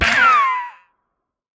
Minecraft Version Minecraft Version 25w18a Latest Release | Latest Snapshot 25w18a / assets / minecraft / sounds / mob / dolphin / death1.ogg Compare With Compare With Latest Release | Latest Snapshot